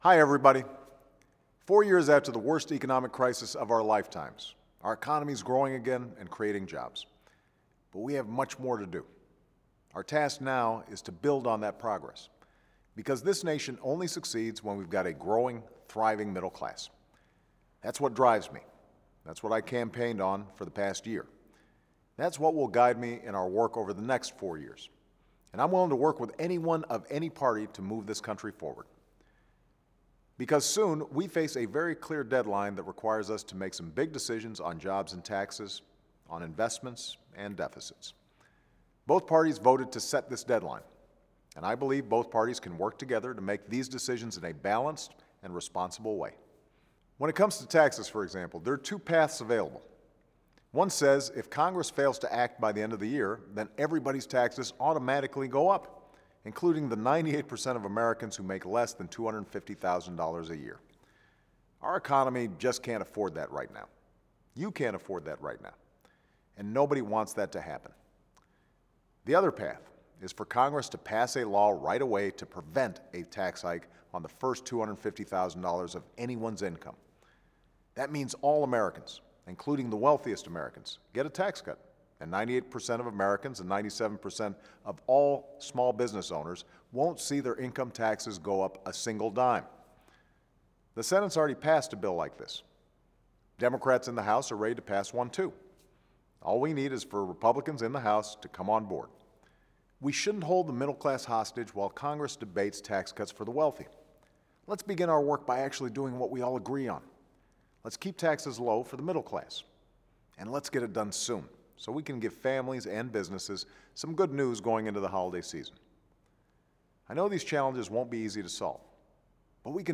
Weekly Address: Working Together to Extend the Middle Class Tax Cuts